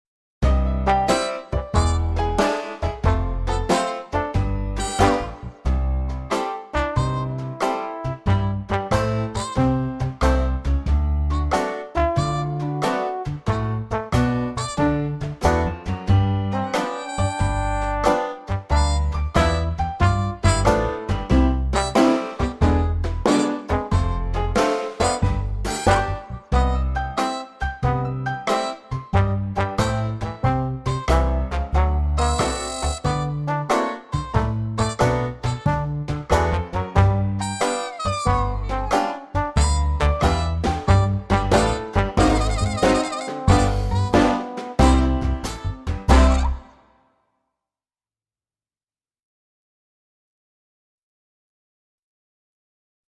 VS Twinkle Toes (backing track)